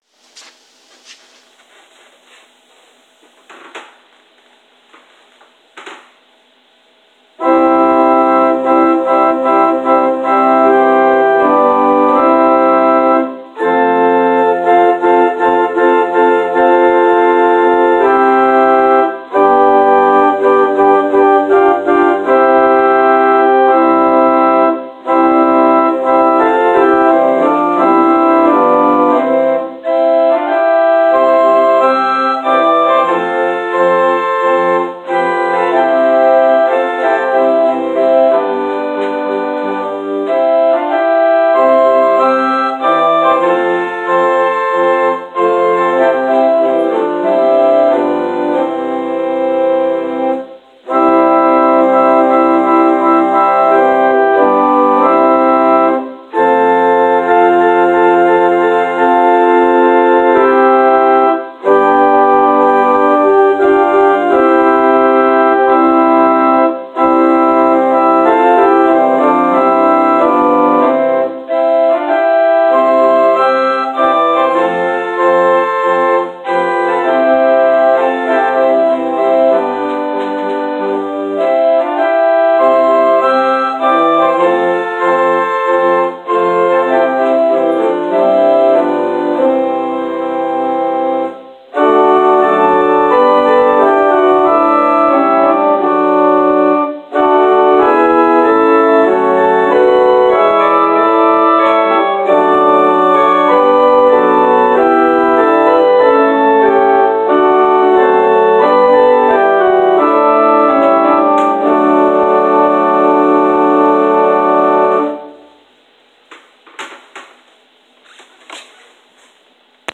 Postlude: “God Be with You till We Meet Again” – William G Tomer, Ralph Vaughan Williams